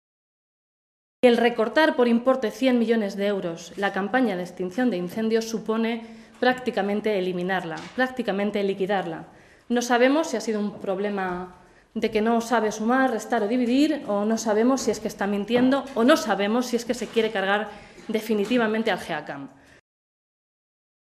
Blanca Fernández, portavoz de Agricultura del Grupo Parlamentario Socialista
Cortes de audio de la rueda de prensa